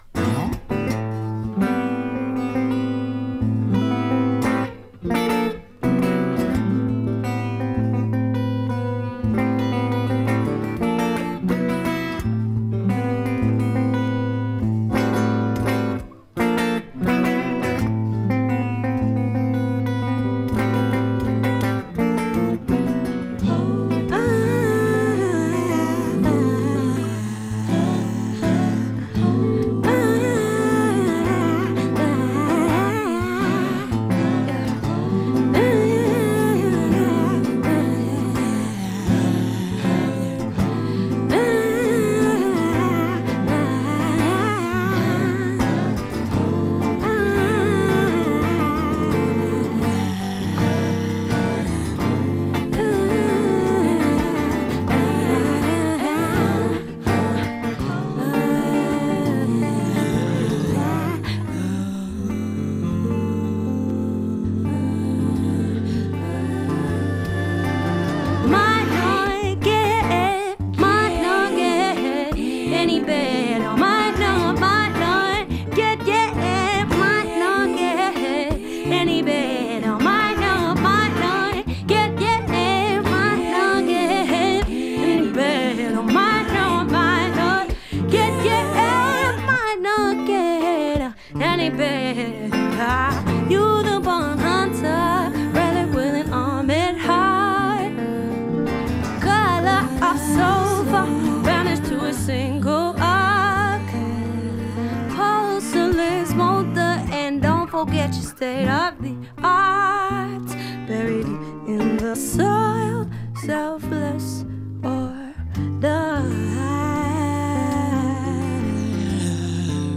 ギターとヴォーカルというシンプルな音づくりによる繊細かつオーガニックな音空間が無限に広がる。